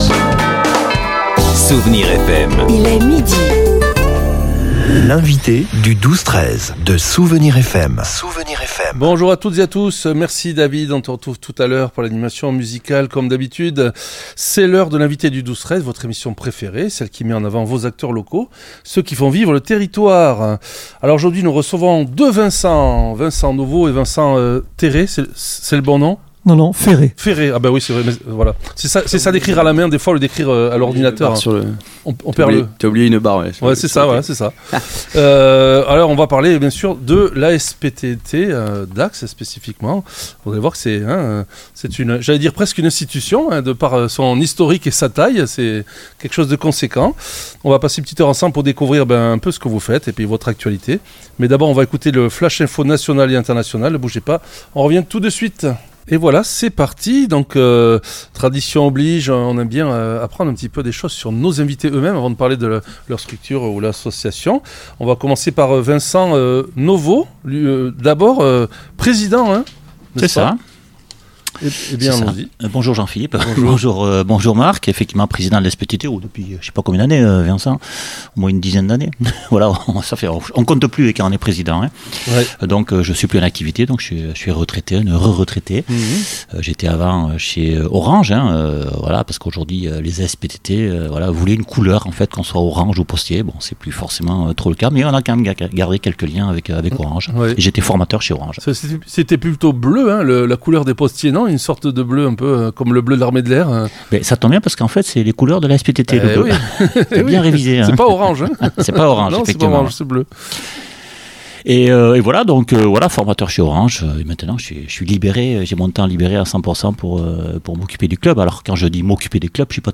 L'entretien a également mis en lumière l'évolution du club vers l'ère numérique avec des services digitaux comme le Digiclub et le Pass ASPTT, facilitant la vie des adhérents. Mais le grand rendez-vous de l'année reste le 6 juin 2026, date à laquelle le club fêtera ses 80 ans , entre adhérents.